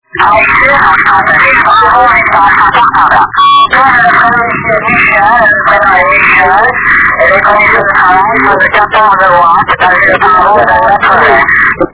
Archivos sonido de QSOs en 10 GHz SSB
374 Kms Tropo Terrestre